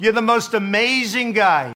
На этой странице собраны аудиозаписи с голосом Дональда Трампа: знаменитые высказывания, фрагменты выступлений и публичных речей.